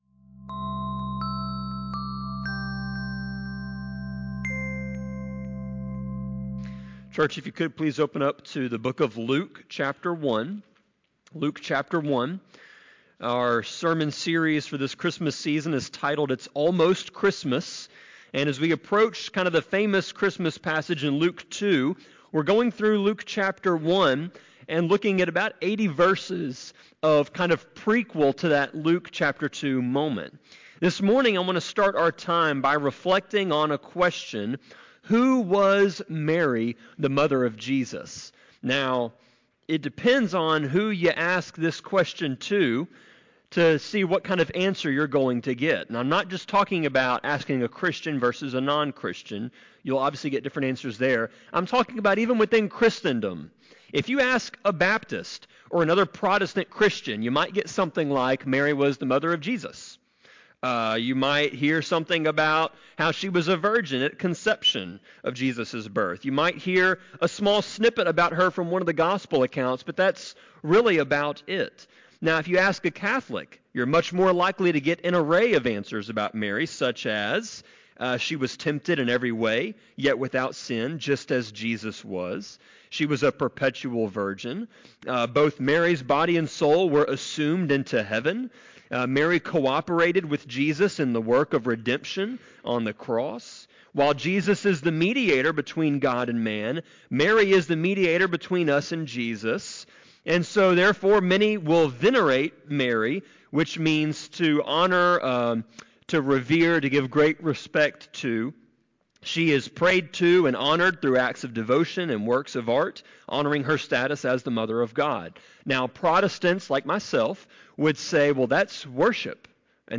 Sermon-24.12.8-CD.mp3